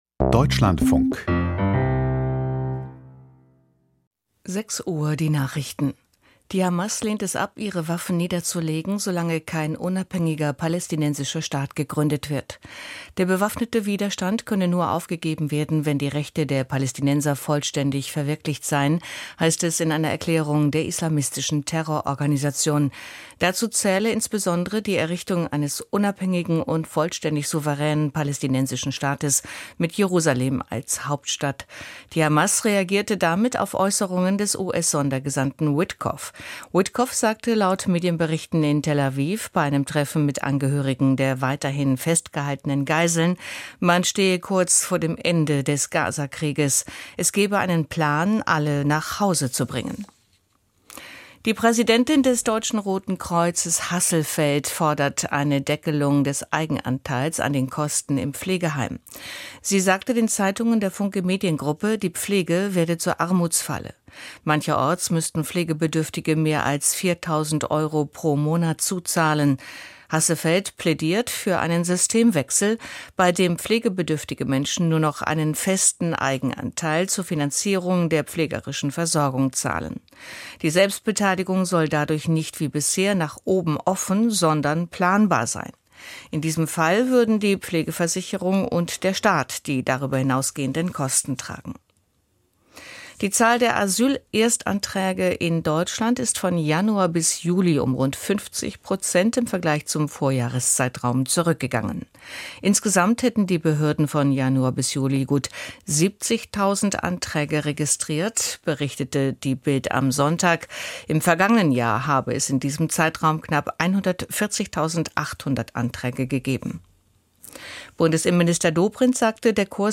Die Nachrichten vom 03.08.2025, 06:00 Uhr
Aus der Deutschlandfunk-Nachrichtenredaktion.